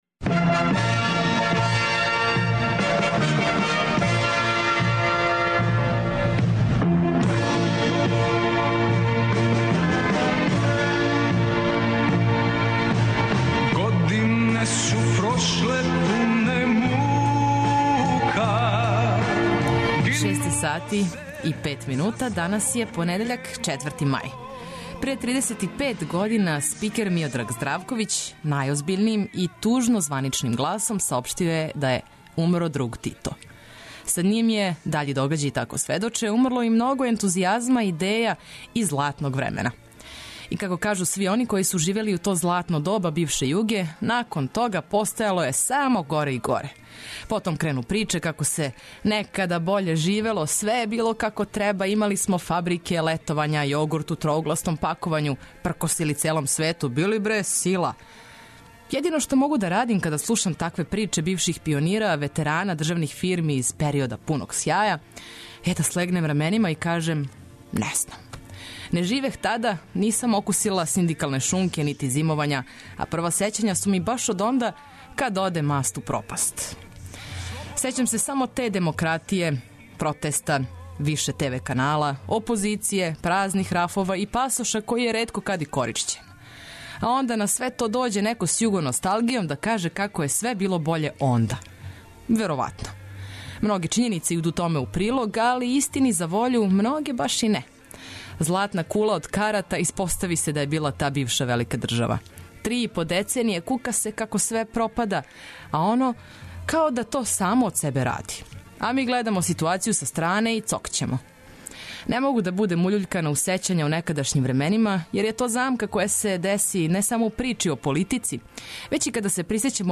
Наш музички маратон је завршен, а и продужени викенд - да се лакше вратите "стању редовном", помажемо вам ведрином и разбуђујућом музиком од 6 ујутру!